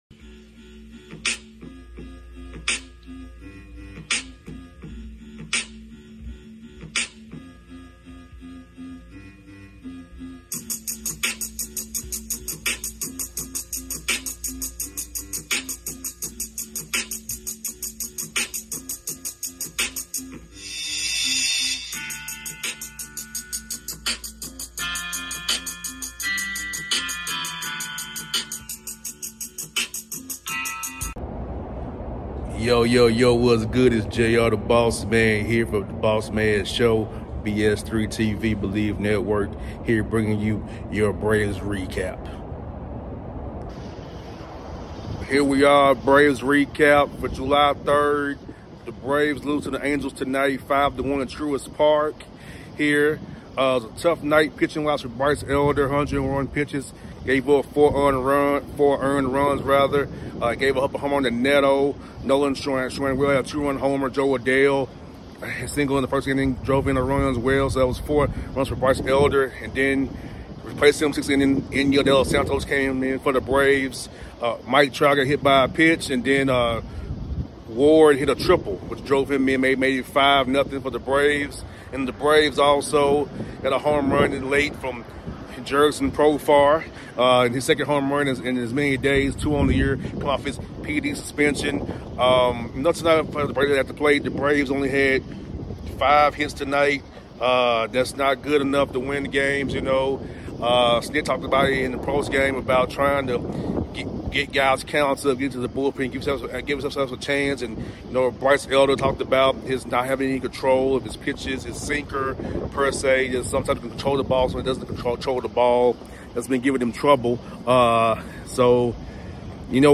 Braves lose 5-1 to the Los Angeles Angels at Truist Park. W: Soriano (6-5) L: Elder (2-6) In addition to my thoughts on the game hear postgame comments from Pitcher Bryce Elder and Manager Brian Snitker.